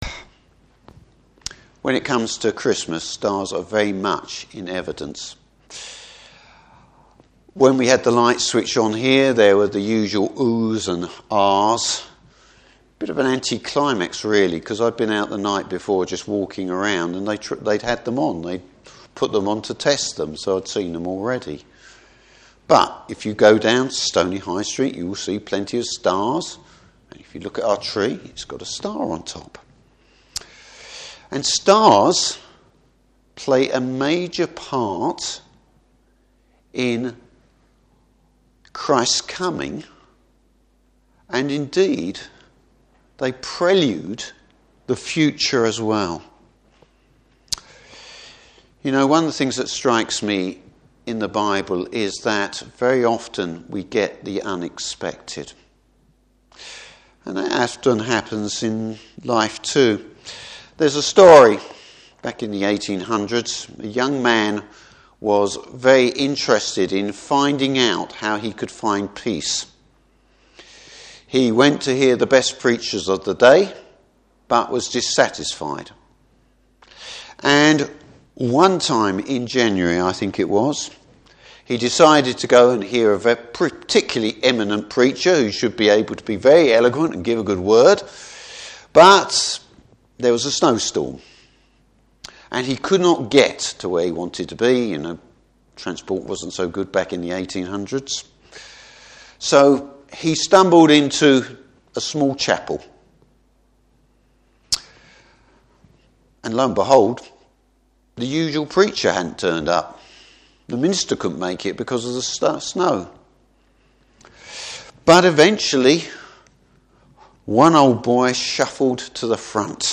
Service Type: Carol Service Jesus, the bright Morning Star.